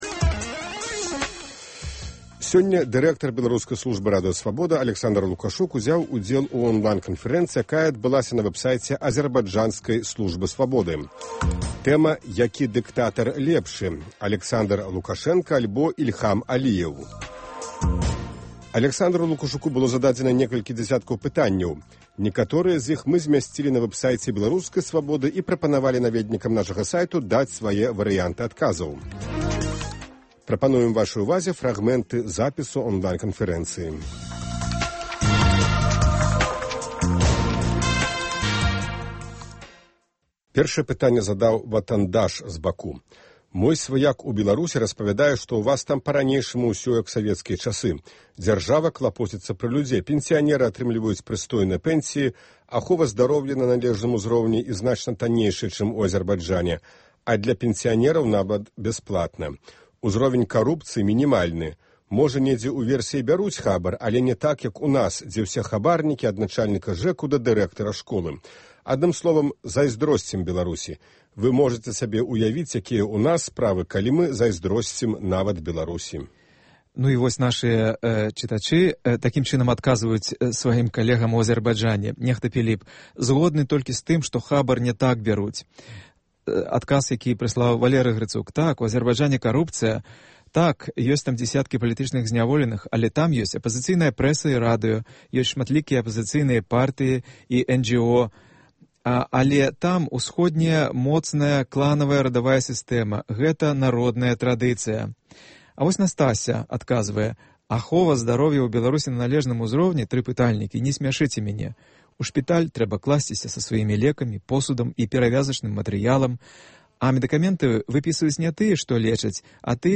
Онлайн- канфэрэнцыя
Запіс онлайн-канфэрэнцыі